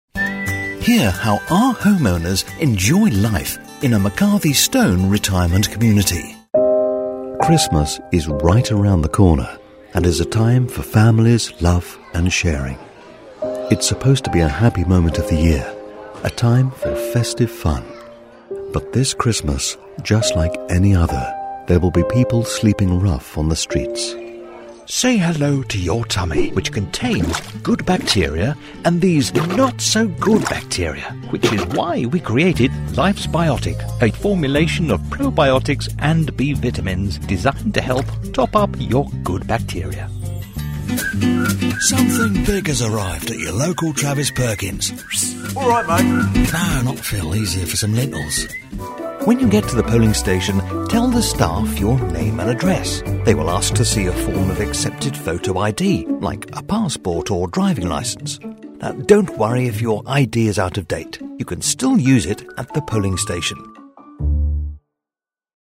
Older Sound (50+)
Warm, articulate British voice with natural authority and clean, confident delivery.
Radio Commercials
Mixed Commercials